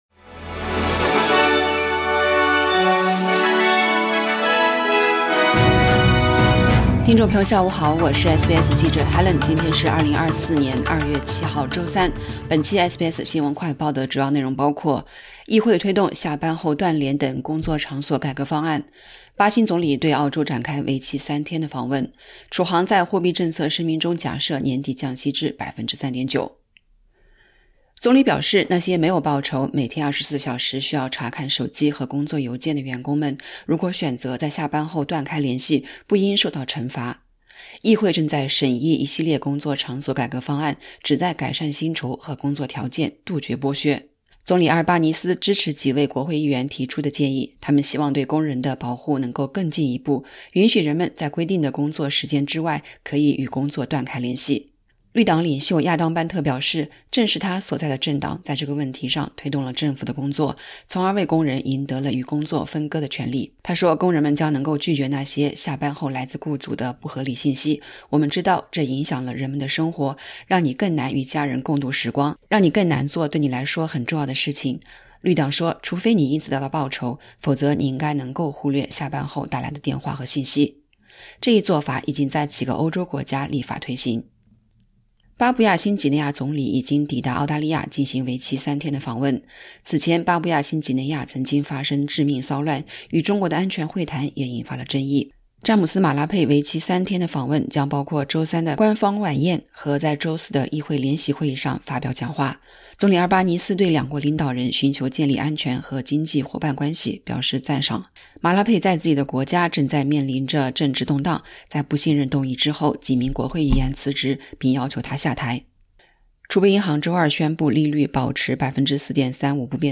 【SBS新闻快报】与工作“断联”：拟议新法让澳洲人有权对老板下班后的工作联系说不